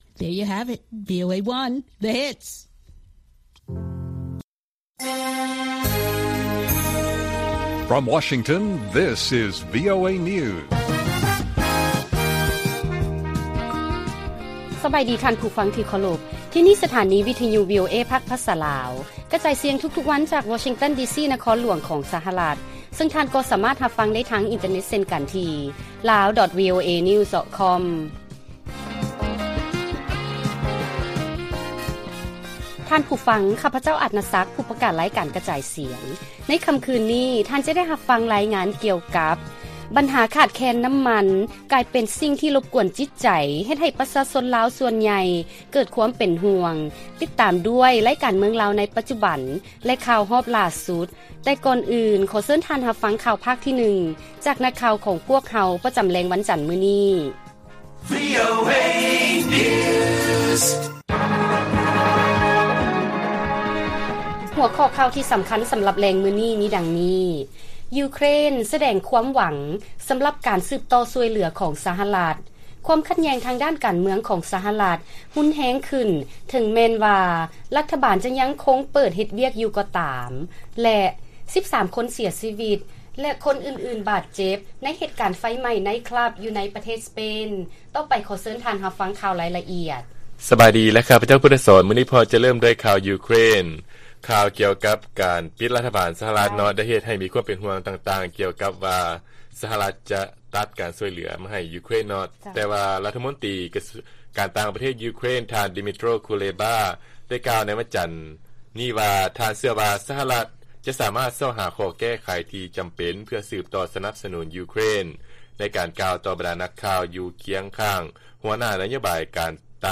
ລາຍການກະຈາຍສຽງຂອງວີໂອເອ ລາວ: ຢູເຄຣນ ສະແດງຄວາມຫວັງສຳລັບການສືບຕໍ່ຊ່ວຍເຫຼືອຂອງ ສະຫະລັດ